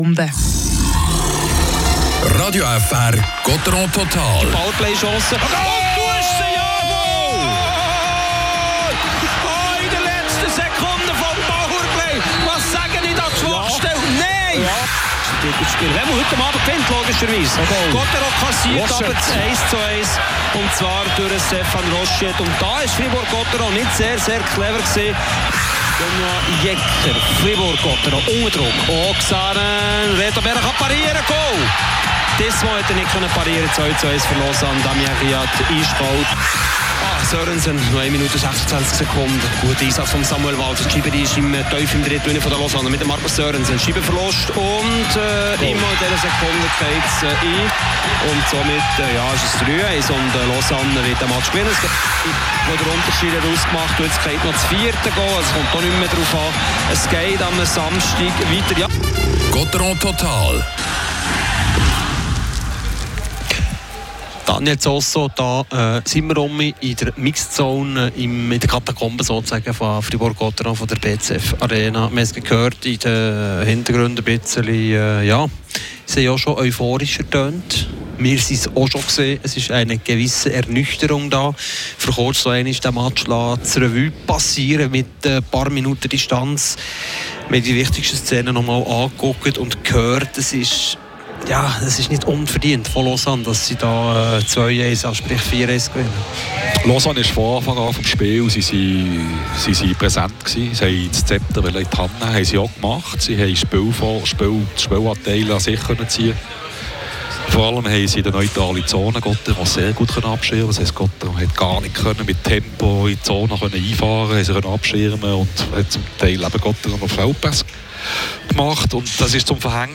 Nach der 1:4-Niederlage blicken Christoph Bertschy und Raphael Diaz im Interview voraus auf das Showdown-Spiel um den Finaleinzug.